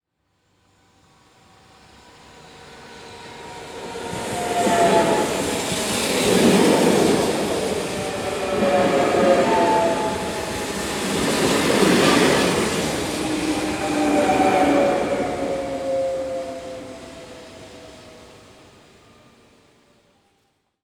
rwd-cache-z-labor.maps.at.rc3.world-sounds-train.wav-55b7da05.mp3